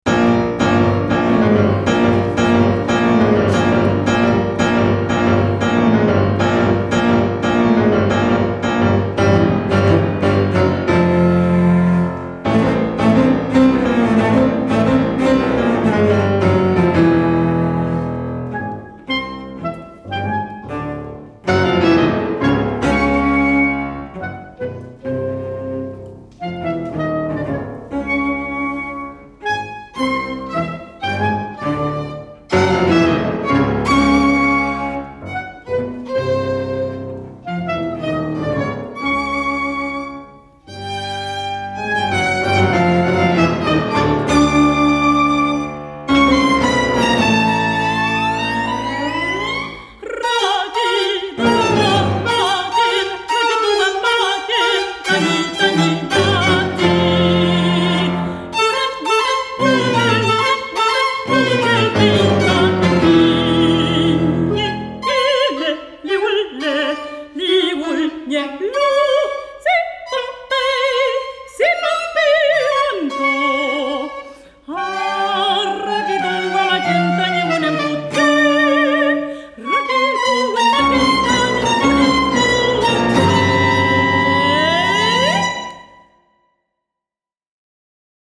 Epigramas Mapuches para contralto, clarinete, violín, cello y piano
Música vocal